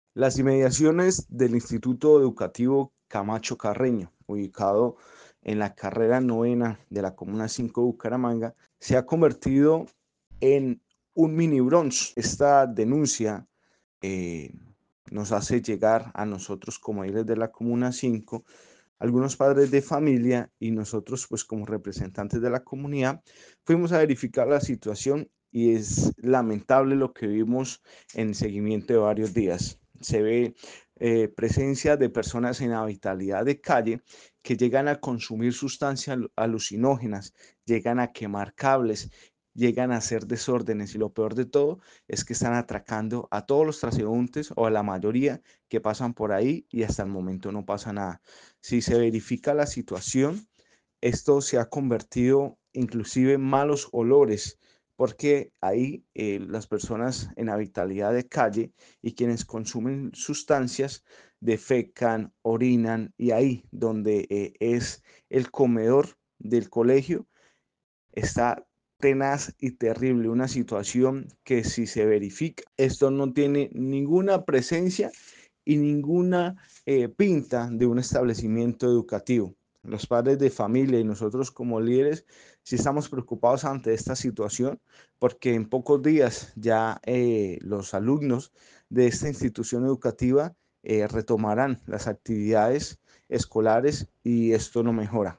VOZ LÍDER COMUNIDAD